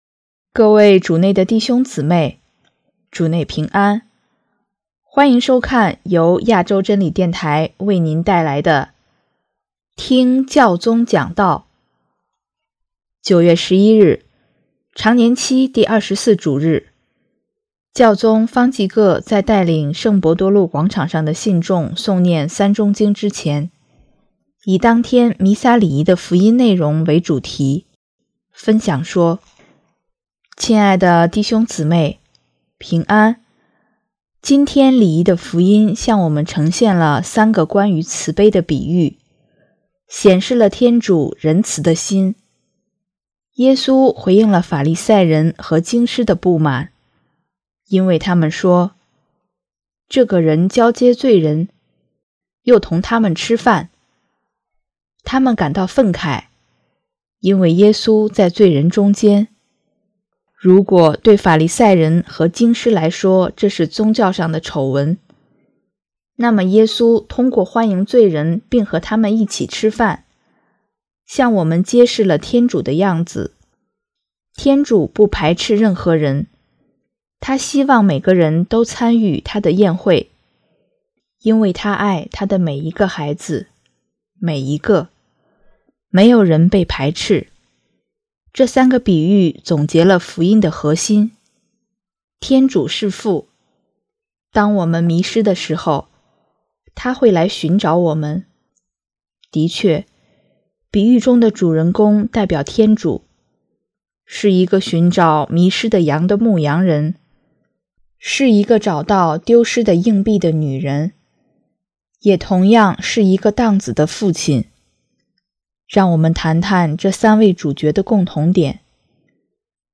首页 / 听教宗讲道/ 新闻/ 教宗方济各